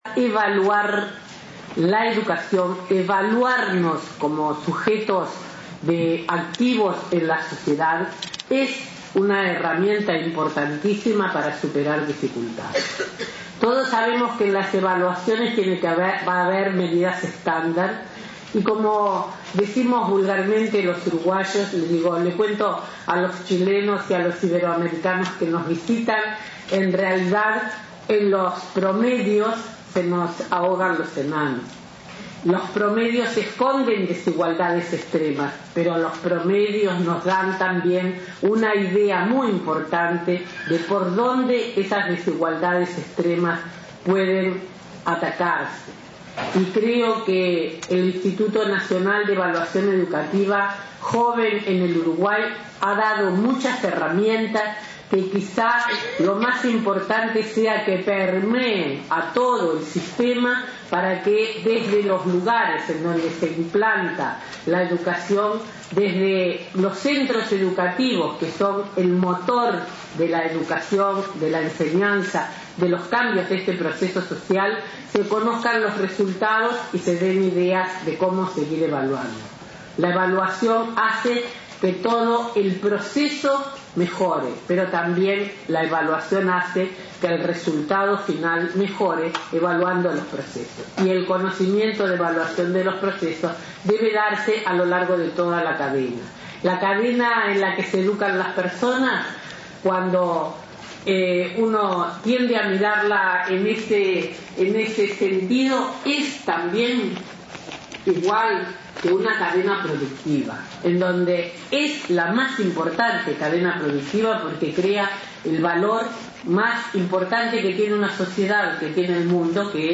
El III Congreso Latinoamericano de Medición y Evaluación Educacional, Colmee 2018, comenzó este jueves en Montevideo con la presencia de investigadores y expertos de 14 países de América Latina. La ministra de Educación y Cultura, María Julia Muñoz, en su discurso de apertura, consideró que evaluar la educación y evaluarnos a nosotros mismos en la sociedad es importante para superar dificultades.